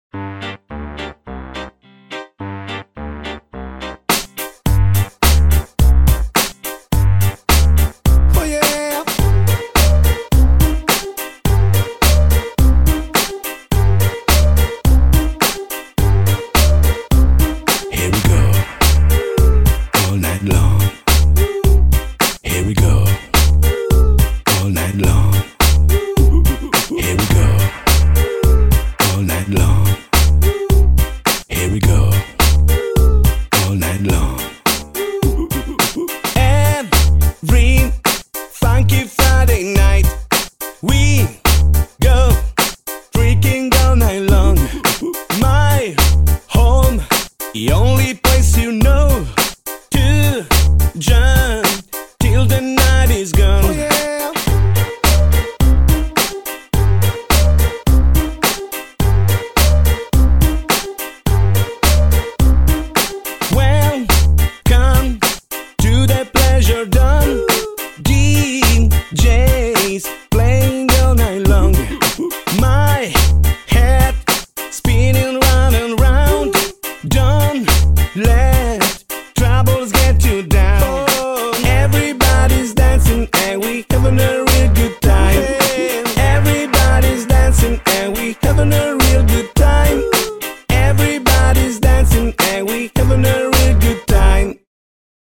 BPM106--1
Audio QualityPerfect (High Quality)